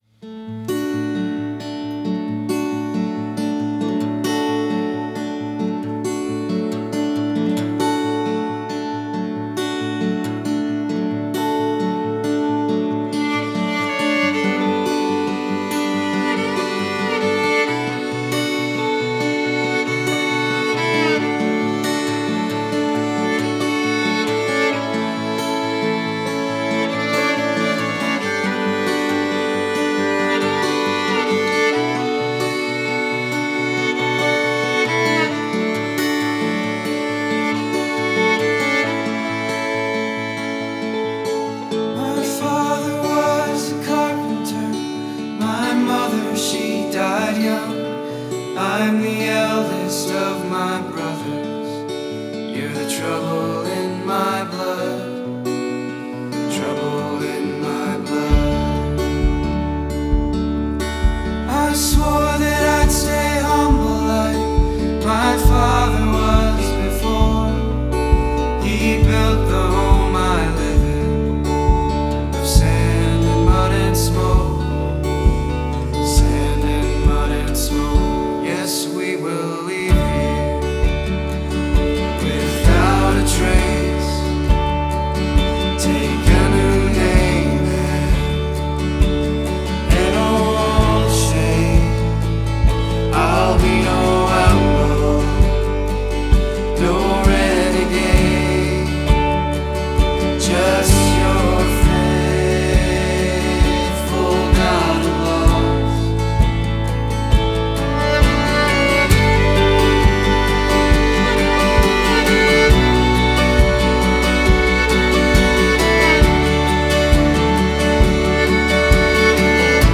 We believe in presenting artists exactly as they perform.